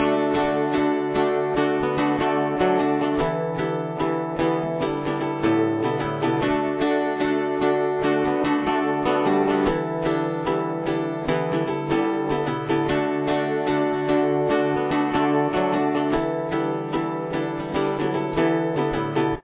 Guitar 2 is tuned to : Standard Tuning
Verse/Chorus